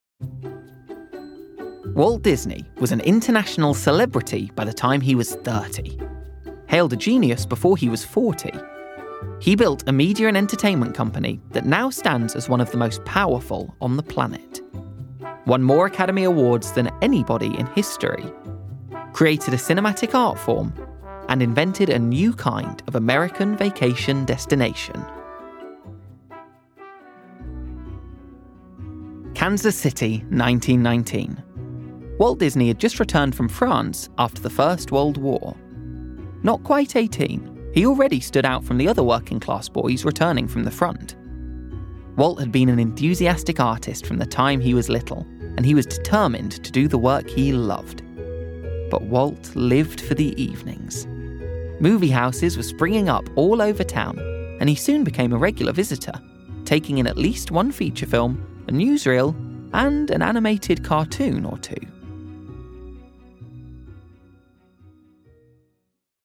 Documentary